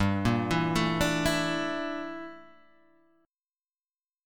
G Minor 6th Add 9th
Gm6add9 chord {3 1 2 2 3 0} chord